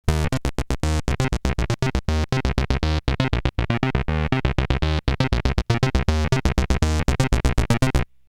HS303_pulse.mp3